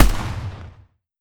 AR2_Shoot 03.wav